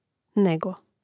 nègo nego1